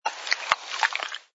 sfx_slurp_glass07.wav